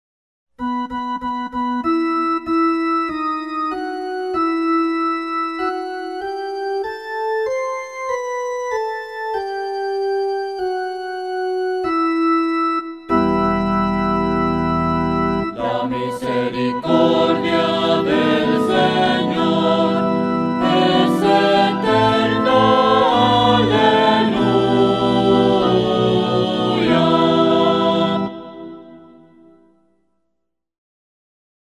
SALMO RESPONSORIAL Del salmo 117 R. La misericordia del Señor es eterna.